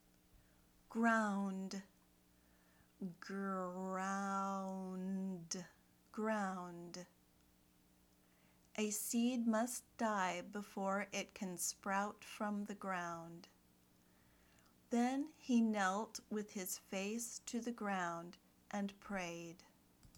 /ɡraʊnd/ (noun)